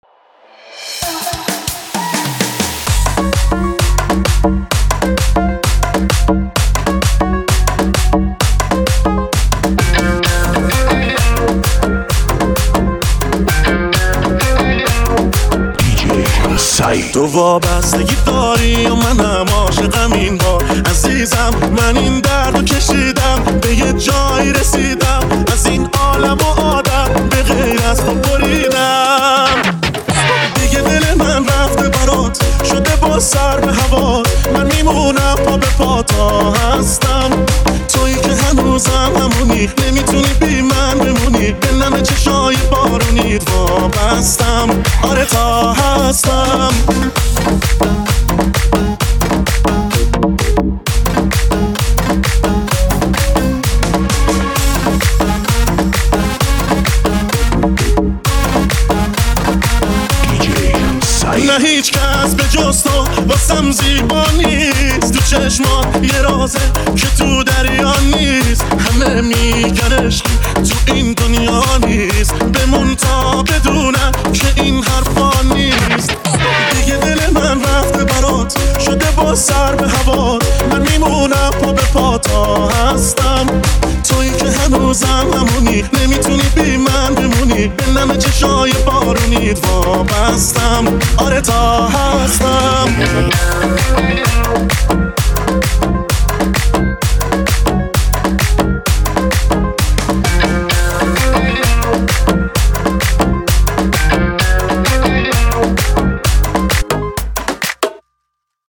ریمیکس شاد تریبال مخصوص رقص